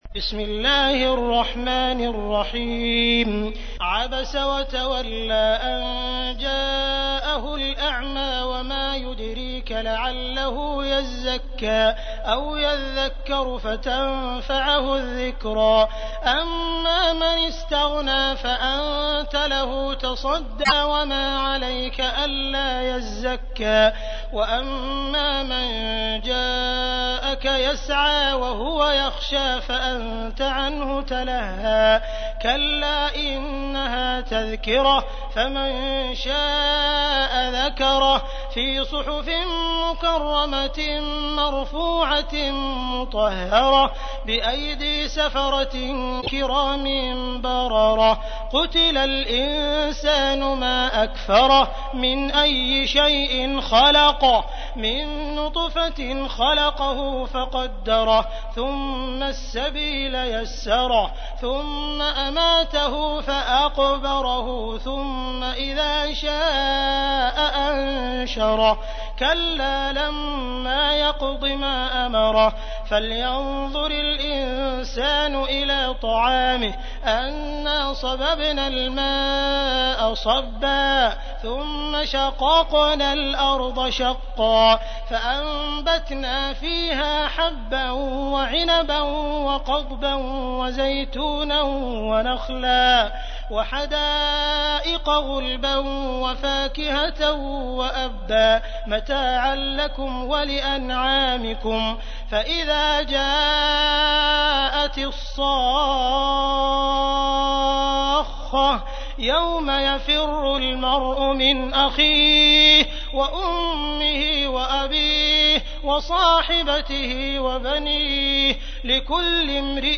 تحميل : 80. سورة عبس / القارئ عبد الرحمن السديس / القرآن الكريم / موقع يا حسين